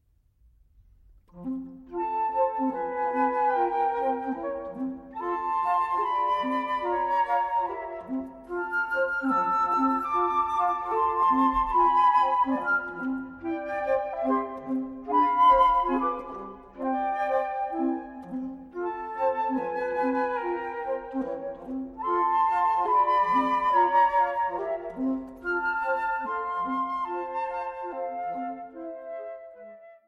Piccoloflöte
Altflöte
Bassflöte
Kontrabassflöte